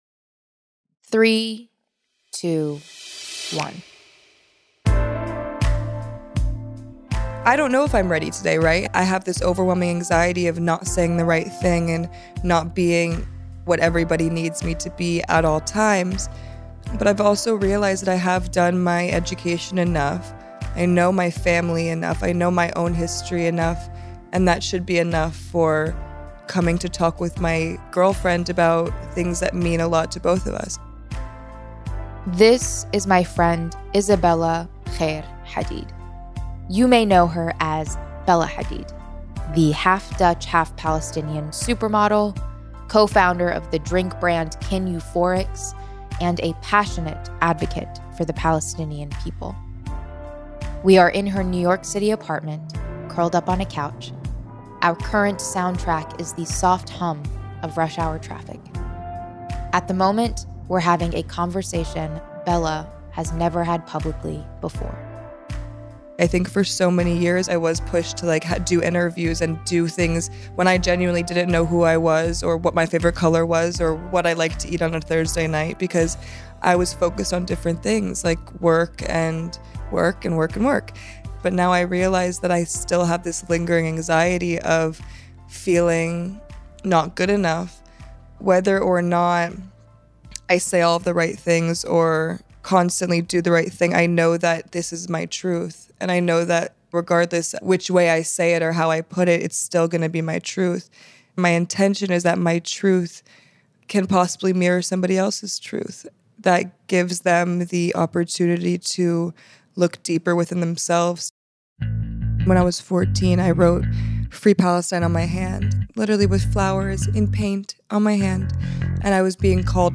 We call them the 3Ps; and they are at play in the stories that define our world and shape our beliefs. Featuring an exclusive interview with Bella Hadid, we consider how Public Opinion affects the lives.